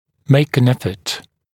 [meɪk ən ‘efət][мэйк эн ‘эфэт]сделать усилие, попытаться